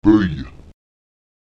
óy [¿I] wird wie ein kurzes ö und ein kurzes i aufeinander folgend artikuliert.
Lautsprecher bóy [b¿I] krank